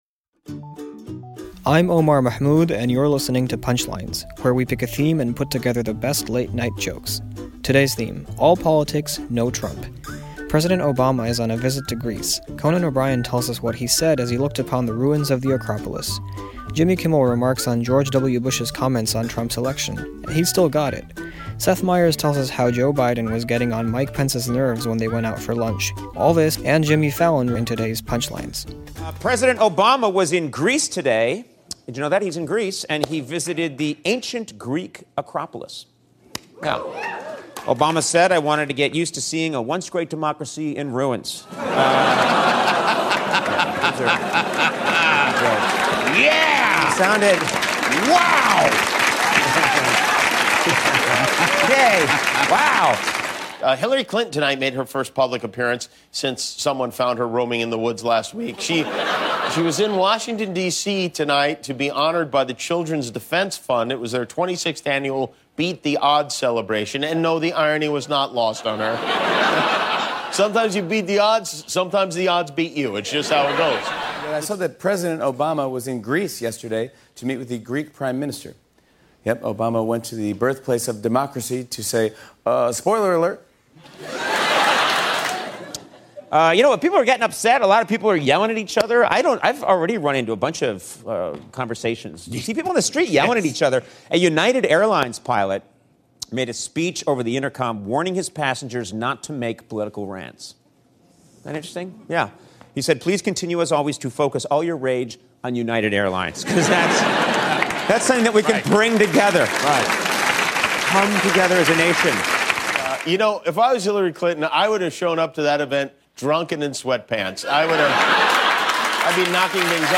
The late-night comics on Hillary Clinton's latest speech, President Obama's trip to Greece and more.